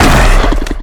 giant_attack_1.ogg